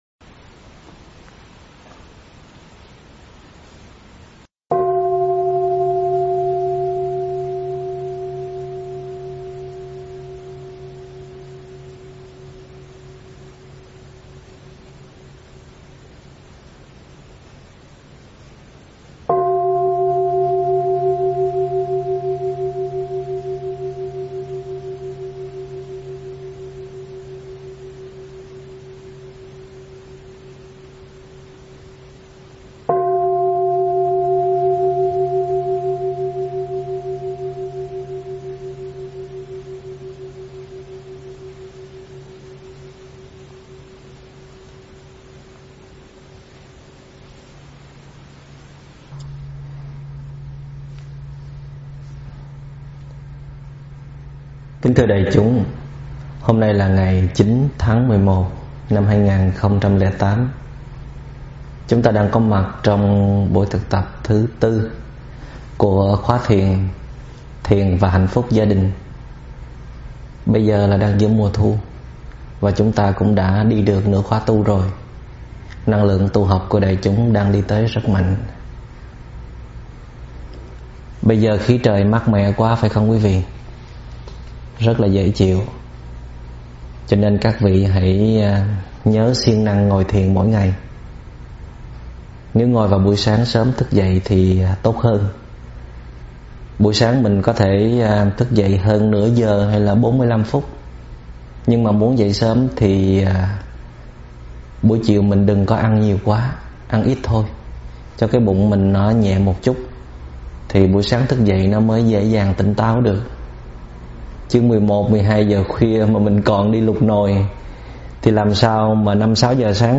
Mời quý phật tử nghe mp3 thuyết pháp Cần Nhau Một Tấm Lòng
giảng tại chùa Hoa Nghiêm, Hoa Kỳ